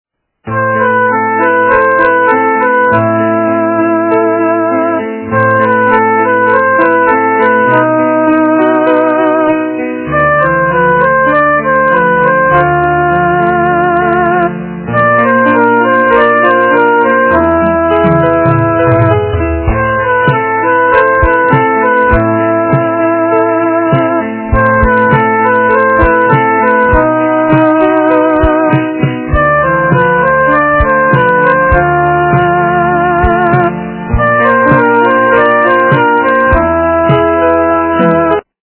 полифоническую мелодию Из м.ф.